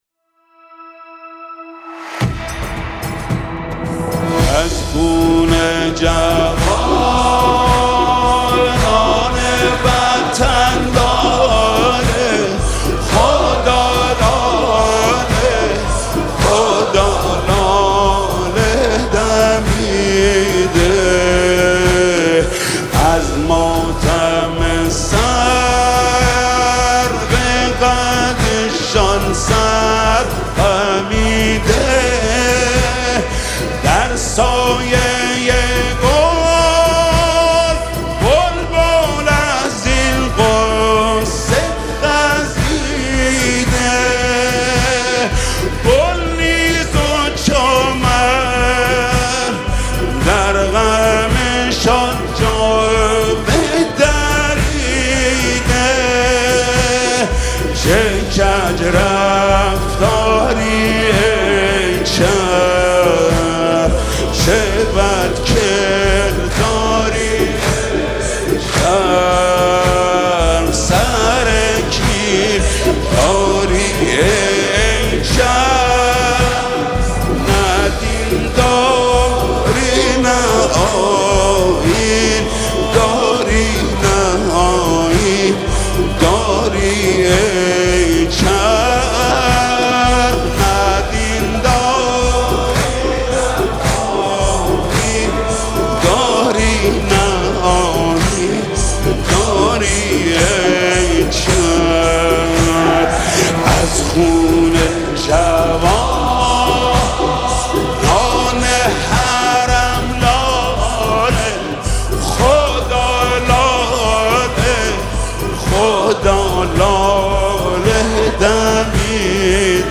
نماهنگ حماسی نماهنگ انقلابی